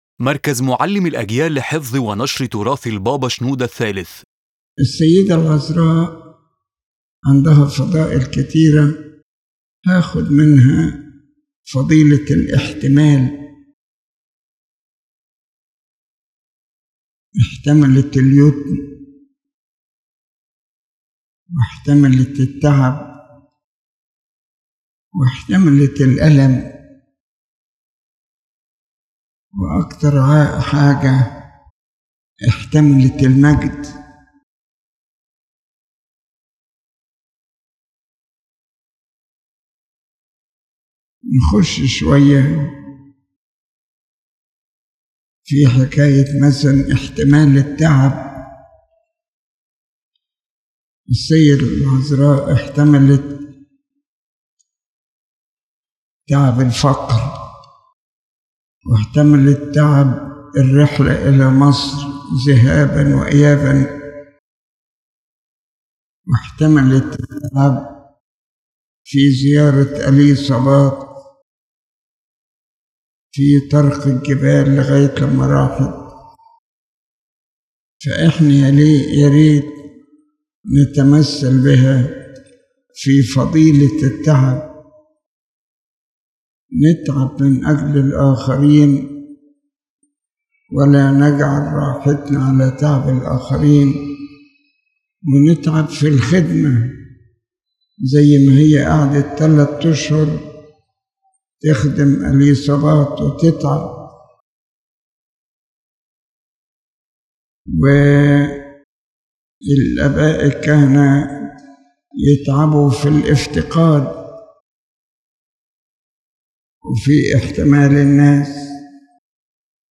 In this profound spiritual sermon, His Holiness Pope Shenouda III speaks about the virtue of endurance, one of the greatest Christian virtues that reveals the depth of faith and love. He begins with the Virgin Mary, who endured orphanhood, hardship, pain, and even the glory of being the Mother of God without pride, keeping all things in her heart with humility and meditation.